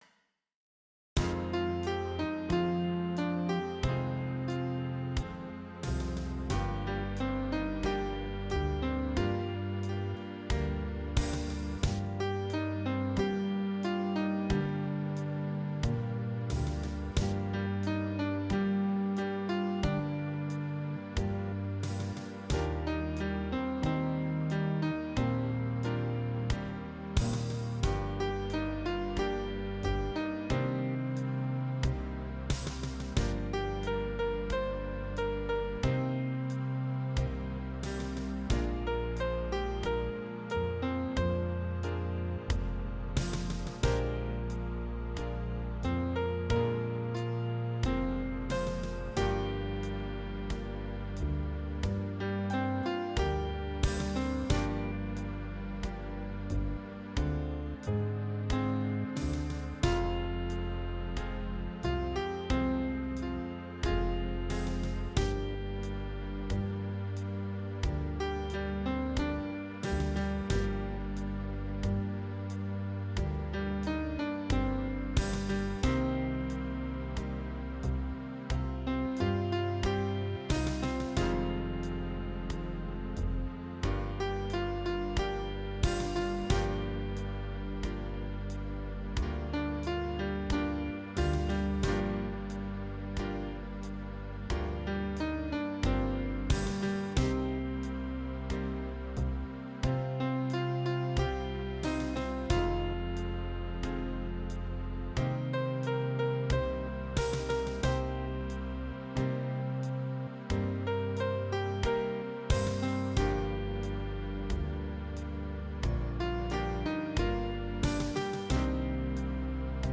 Tr�nh b�y Suno AI